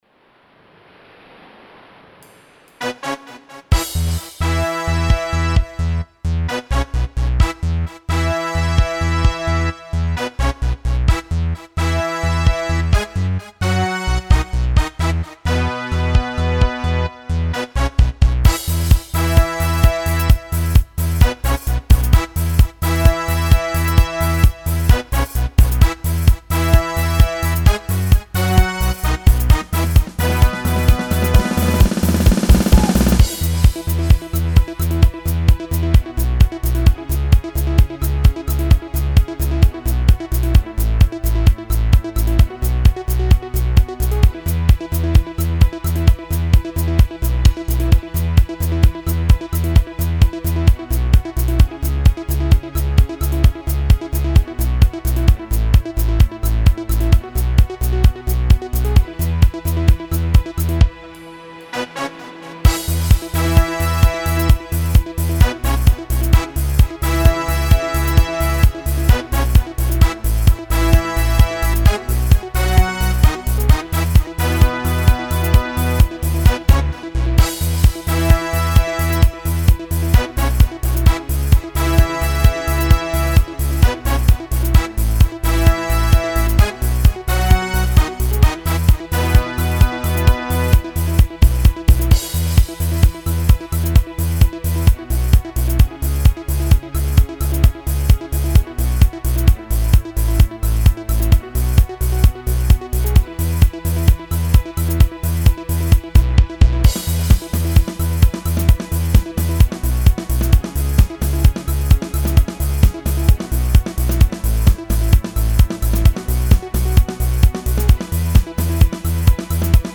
минусовка версия 40846